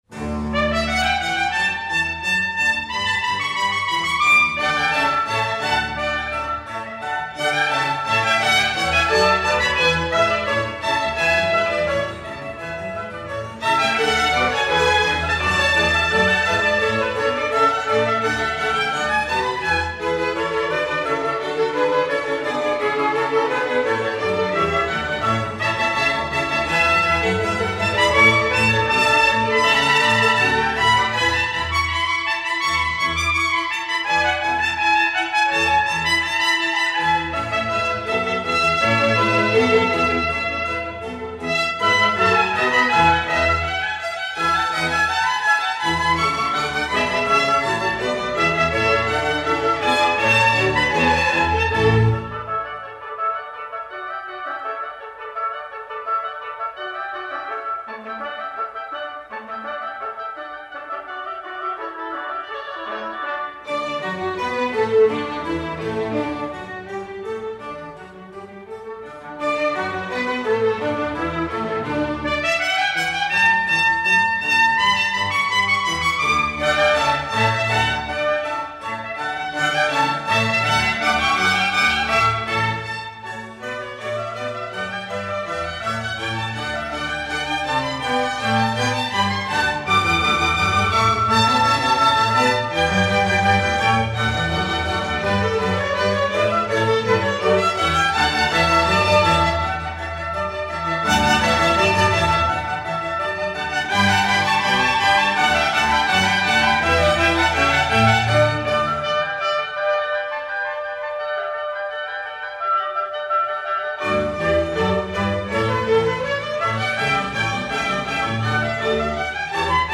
(STEREO)